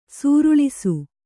♪ sūruḷisu